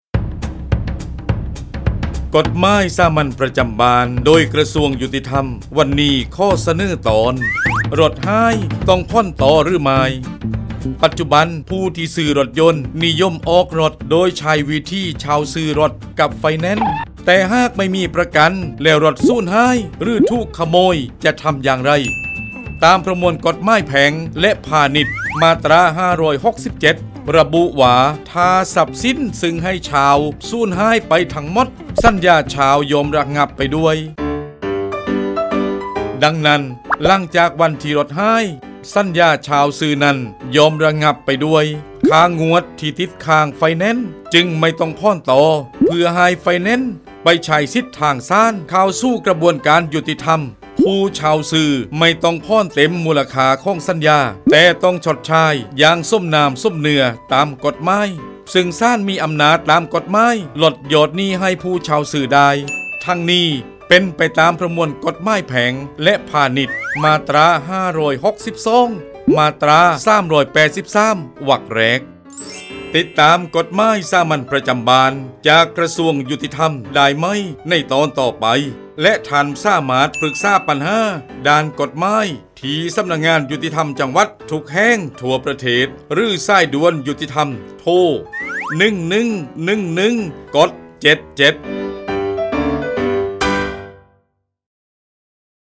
กฎหมายสามัญประจำบ้าน ฉบับภาษาท้องถิ่น ภาคใต้ ตอนรถหาย ต้องผ่อนต่อหรือไม่
ลักษณะของสื่อ :   บรรยาย, คลิปเสียง